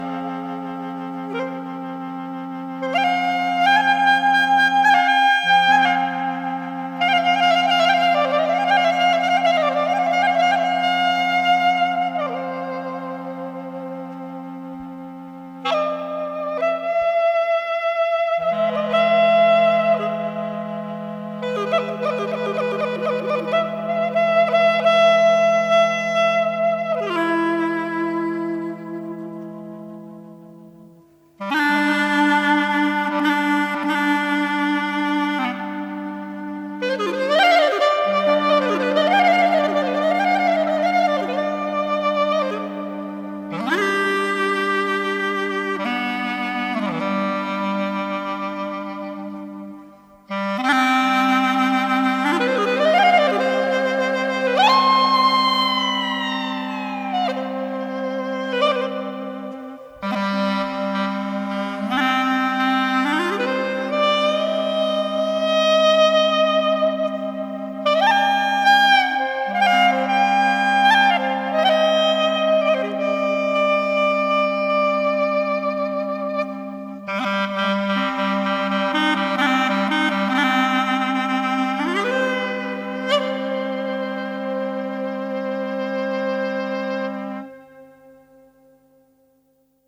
Buenas melodías klezmer